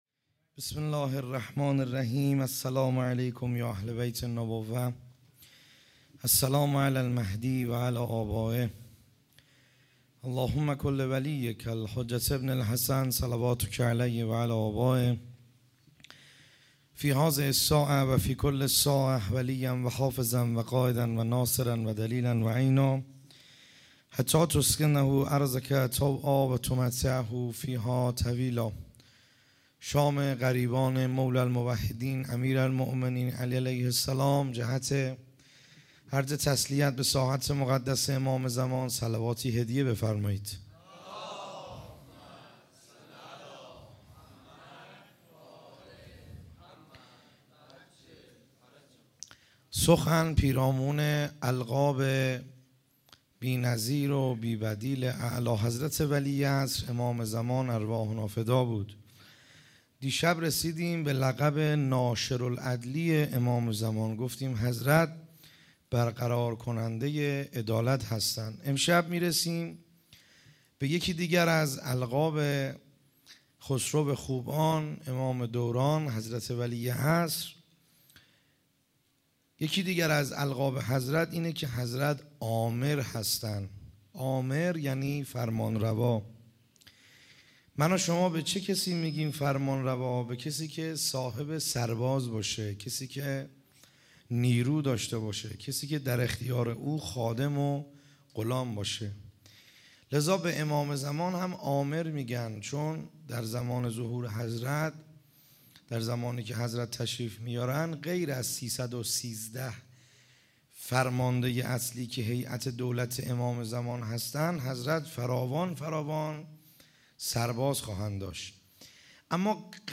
خیمه گاه - بیرق معظم محبین حضرت صاحب الزمان(عج) - سخنرانی ا شب دهم